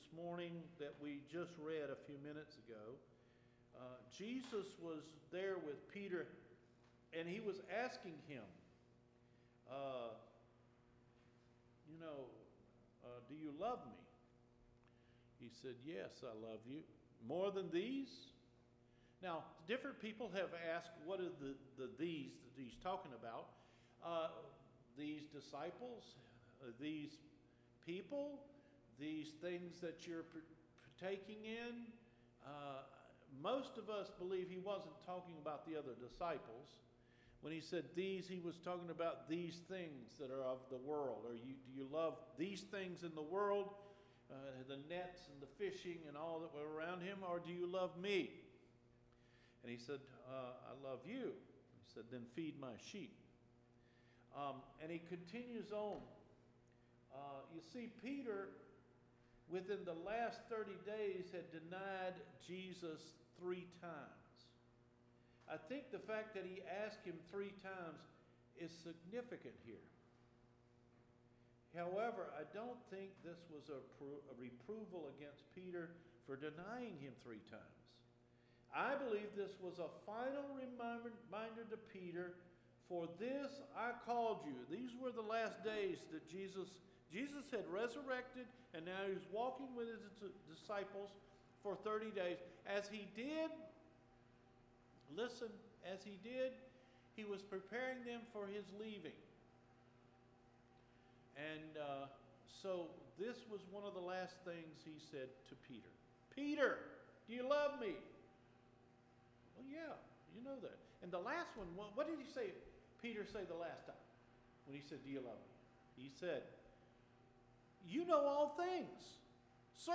ENCOUNTERING JESUS’ PROMISE OF LOVE – FEBRUARY 14 SERMON